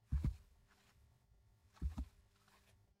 Sneaking on Carpet
Soft, careful footsteps sneaking across thick carpet with muffled, quiet impacts
sneaking-on-carpet.mp3